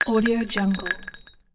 دانلود افکت صدای قطعه فلزی روی آسفالت
به دنبال افکت صدای کاملاً واقعی و رسا برای قطعه فلزی که روی آسفالت کشیده می‌شود هستید؟
Sample rate 16-Bit Stereo, 44.1 kHz
Looped No